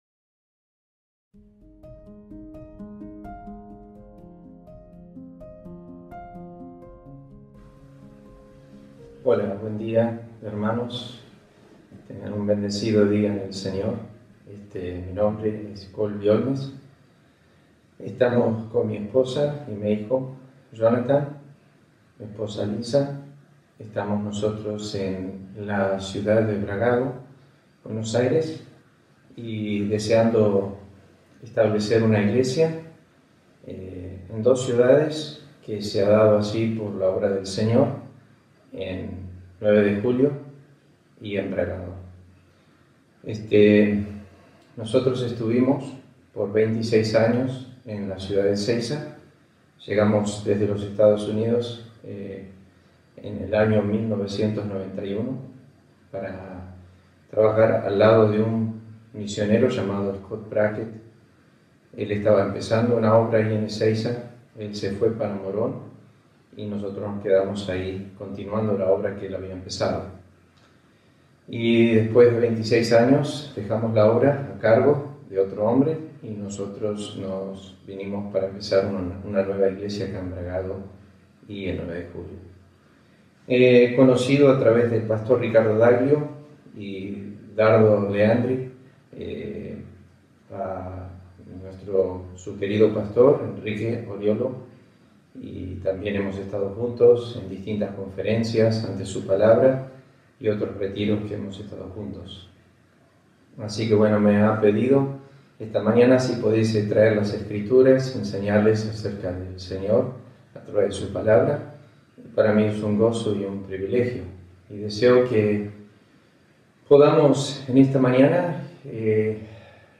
agosto 16, 2020 Sermón ¿Que sostenía a Pablo en el sufrimiento?